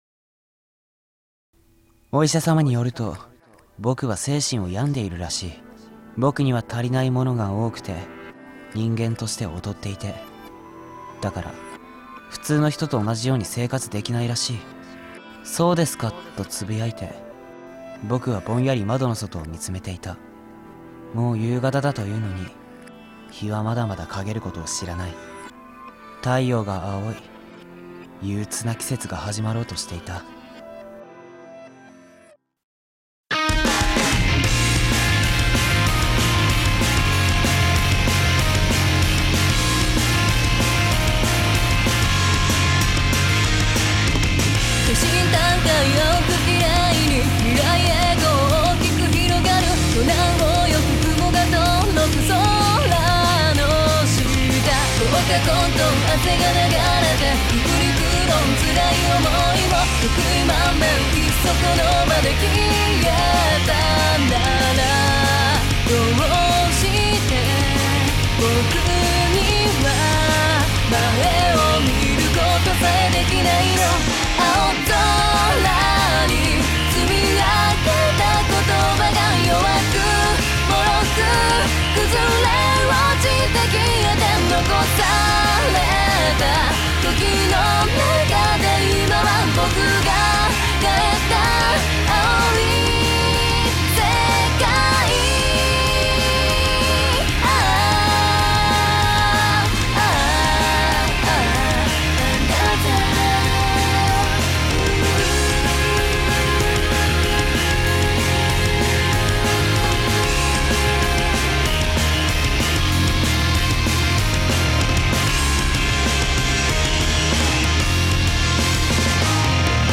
第二回 – オーディオドラマ「アンダー・ザ・ブルー」 – Podcast